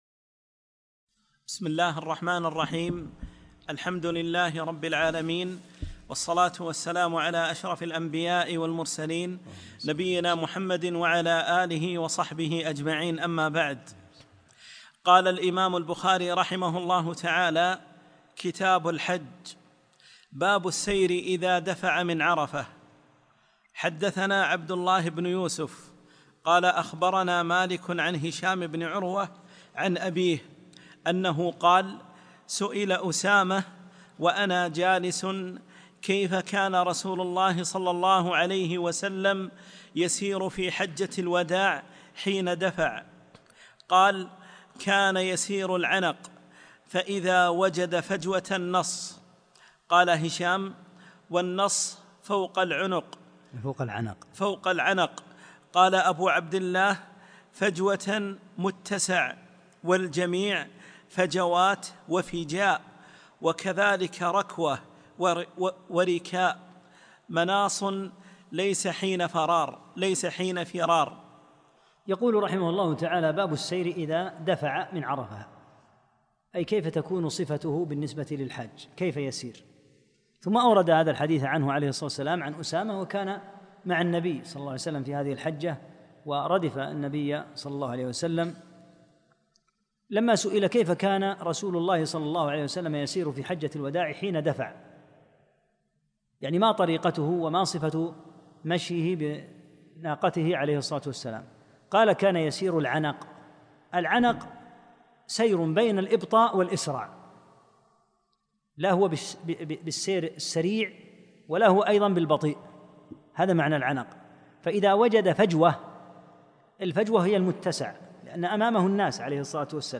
14- الدرس الرابع عشر